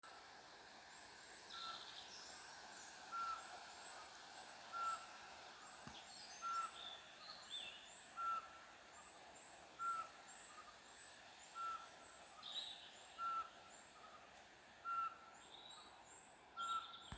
Putni -> Pūces ->
Apodziņš, Glaucidium passerinum
StatussDzied ligzdošanai piemērotā biotopā (D)